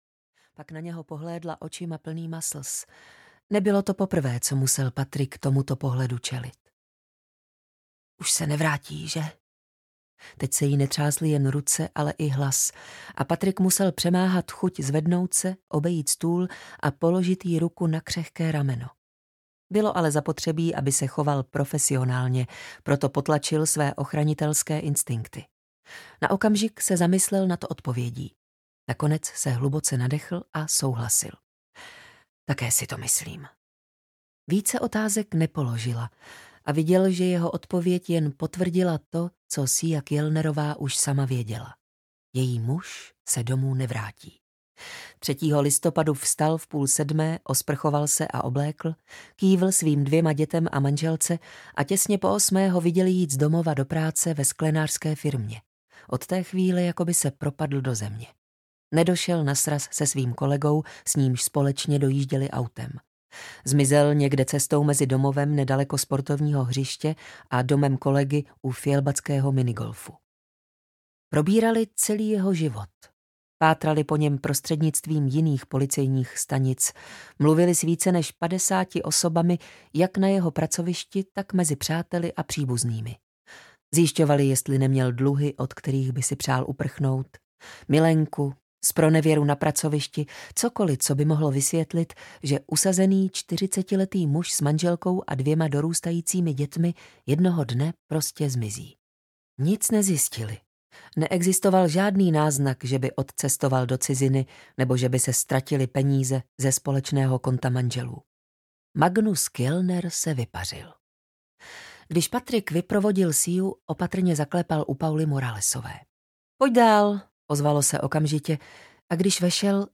Mořská panna audiokniha
Ukázka z knihy